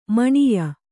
♪ maṇiya